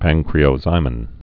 (păngkrē-ō-zīmĭn, păn-)